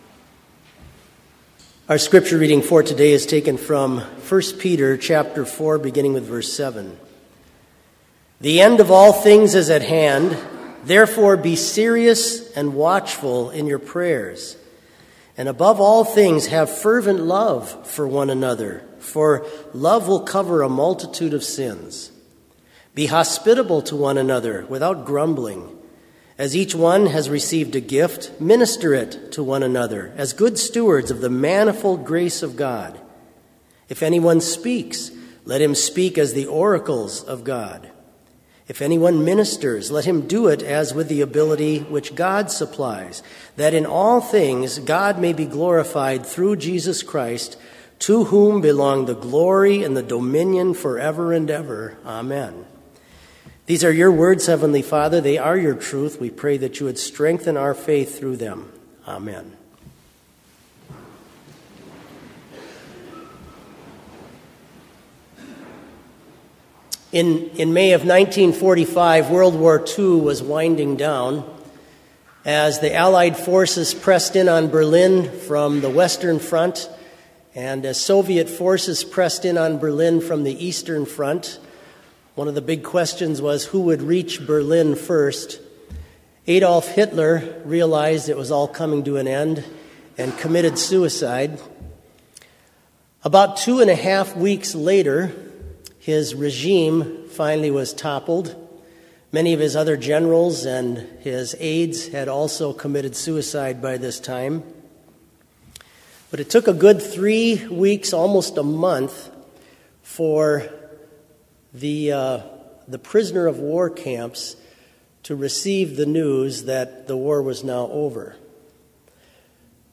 Complete service audio for Chapel - November 16, 2018